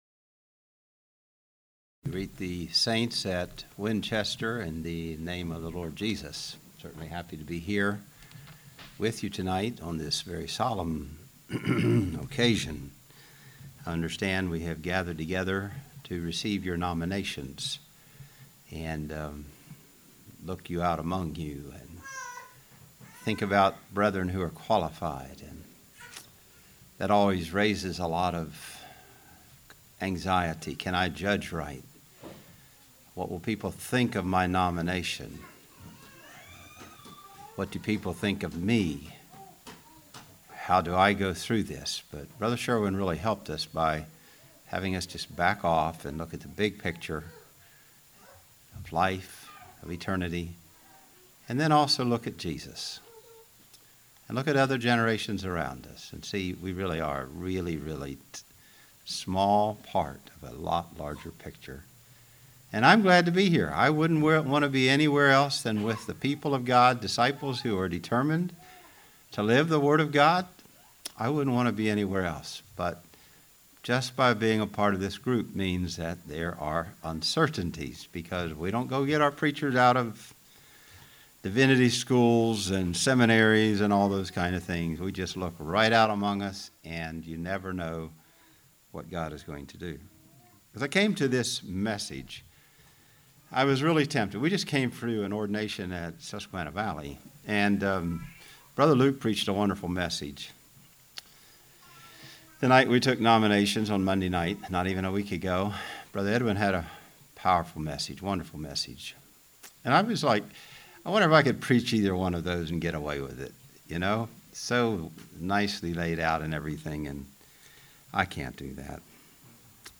Congregation: Winchester
Sermon